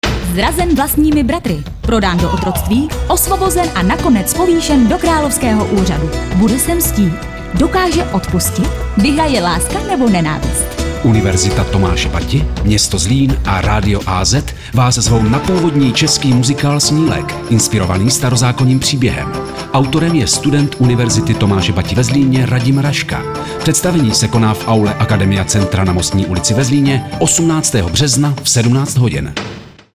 Reklamní spot - AZ Rádio (*.mp3)